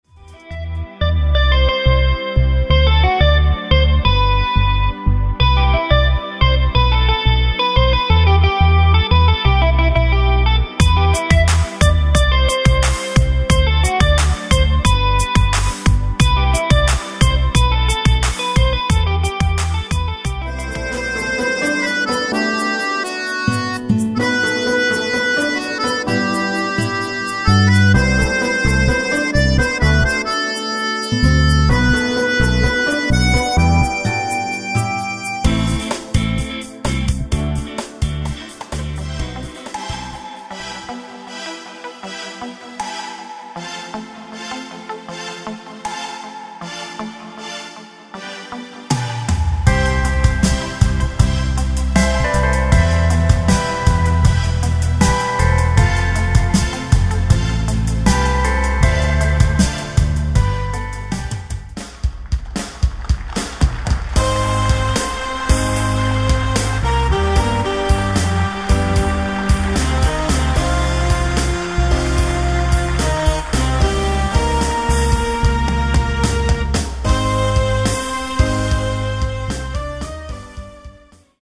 Демо микс написанных мною аранжировок